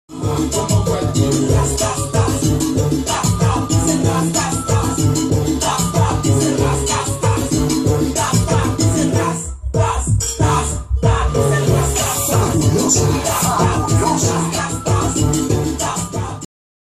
Llatina
Música i indicatiu de la ràdio